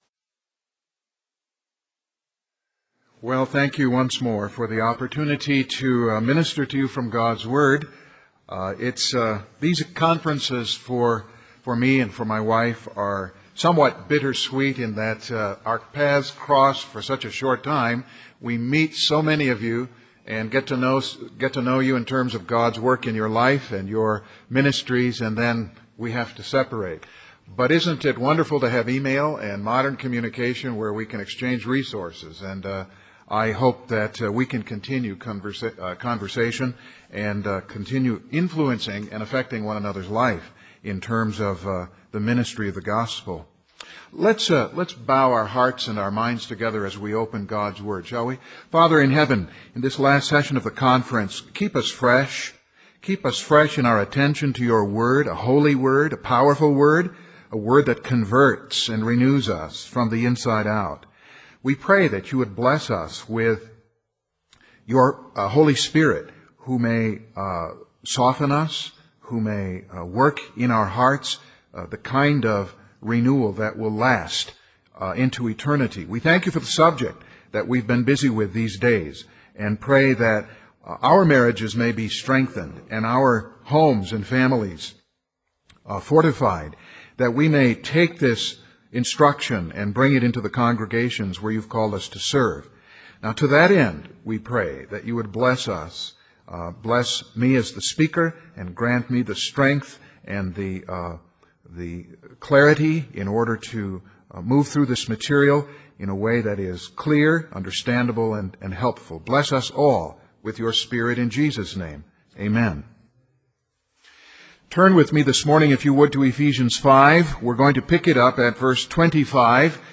Sermons | Grace Minister's Conference